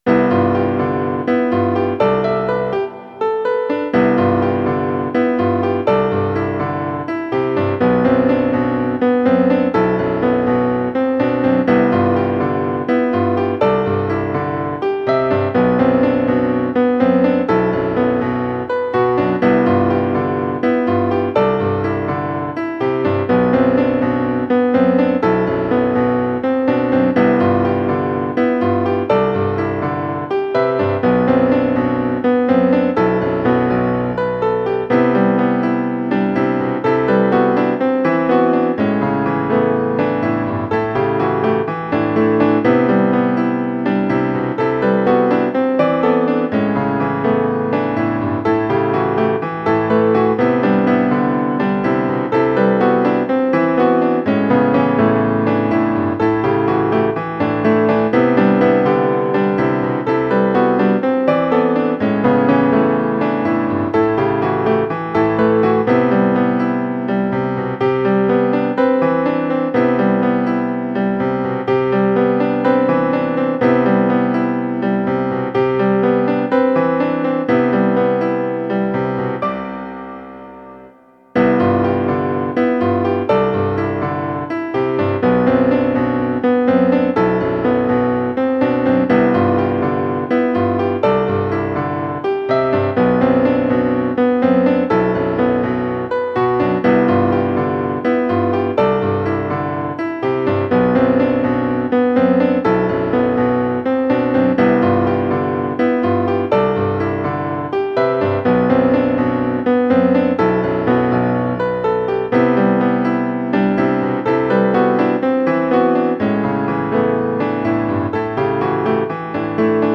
ミディアムテンポのジブリ風ピアノソロを無料音楽素材として公開しています。
イメージ：空　ジャンル：ジブリっぽいピアノソロ
のんびり飛ぶイメージなので、それほどテンポは速くないです。